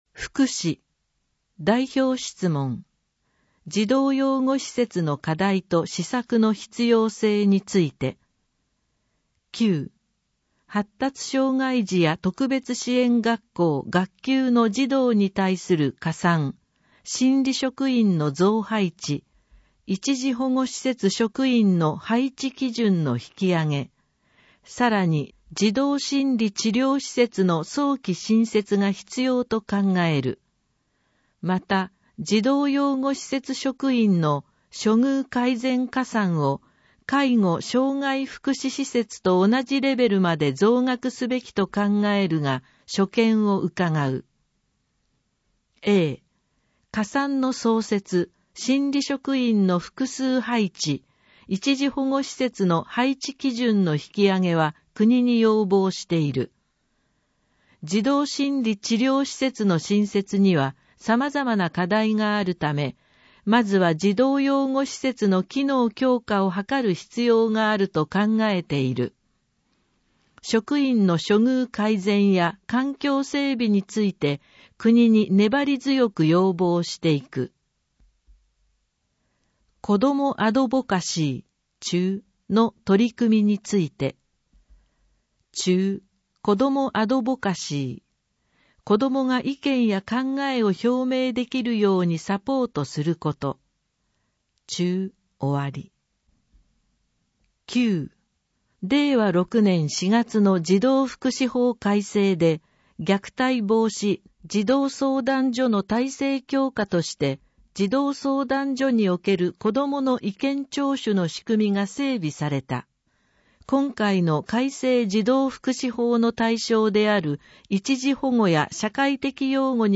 「Windows Media Player」が立ち上がり、埼玉県議会だより 177号の内容を音声（デイジー版）でご案内します。